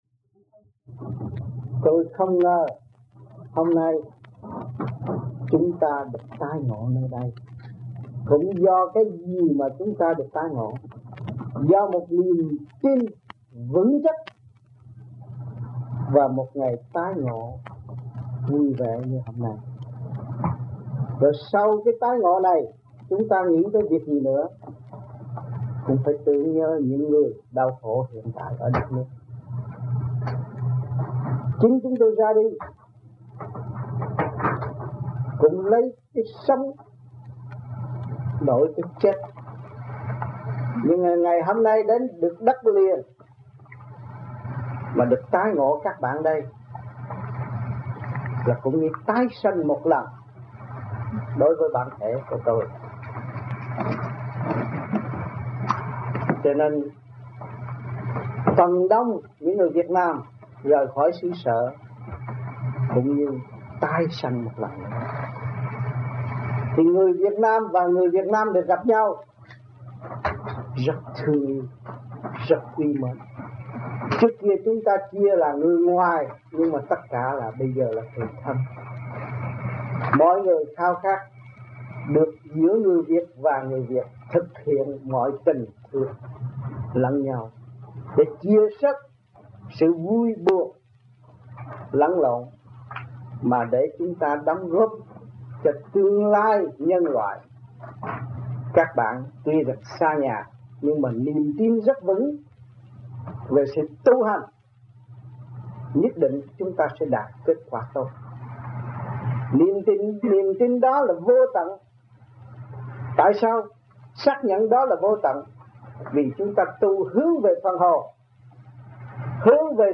1979 Đàm Đạo